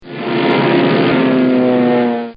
airplane+biplane+1
Category: Sound FX   Right: Personal